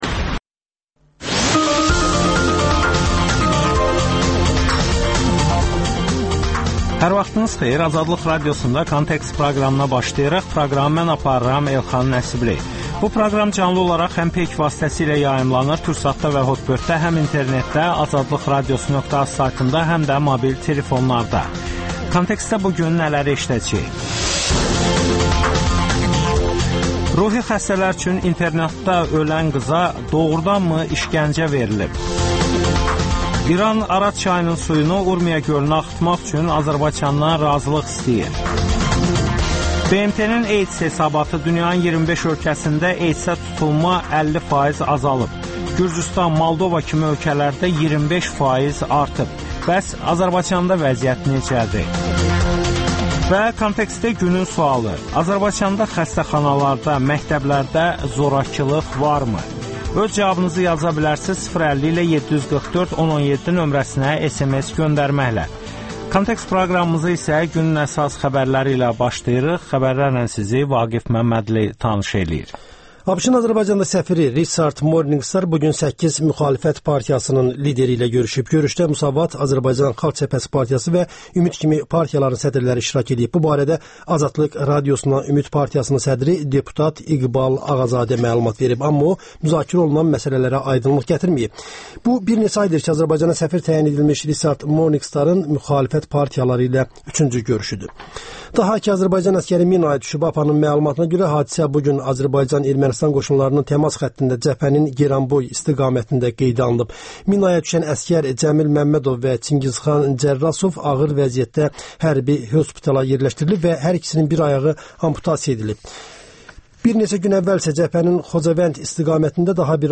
Müsahibələr, hadisələrin müzakirəsi, təhlillər